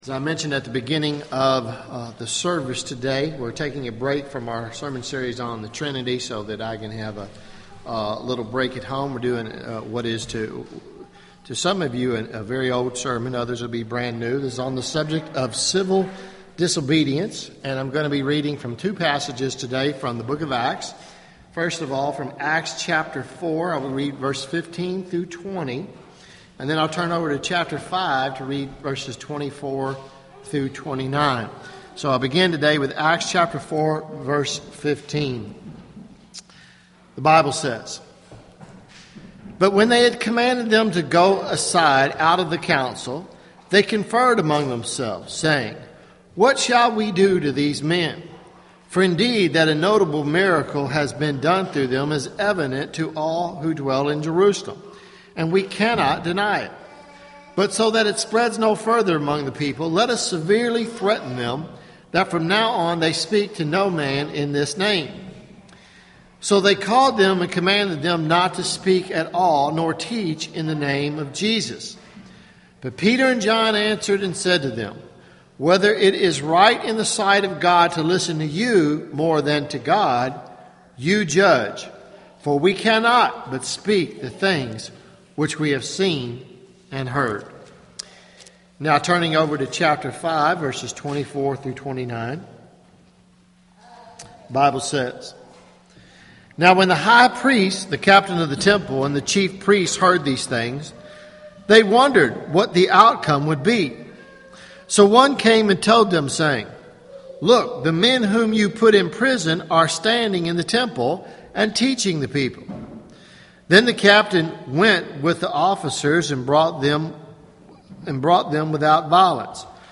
Sermons Jul 14 2013 Preached July 14